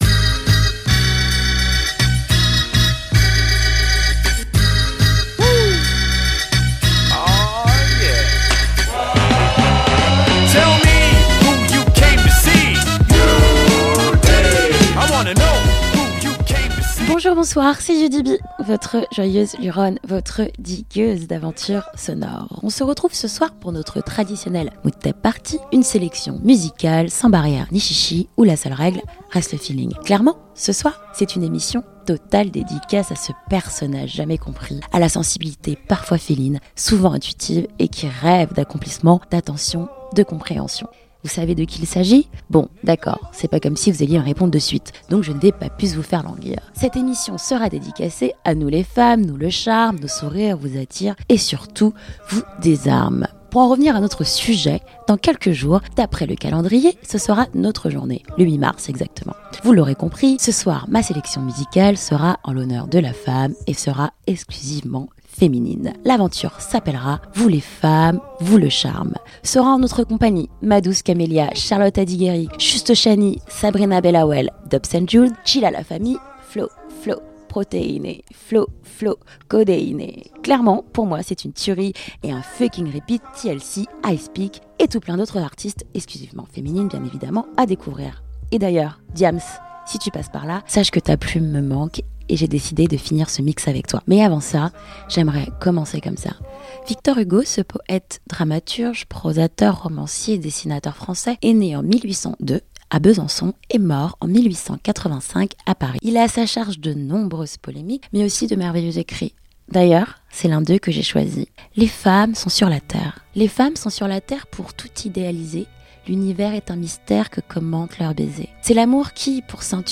Il est important de préciser, qu'ici, on ne croit pas au "genre musical", mais au feeling, à la mélodie, à la vibe et surtout au travail. Mood Tapes #20 : Une Nouvelle sélection de mes "coups de coeur" de la semaine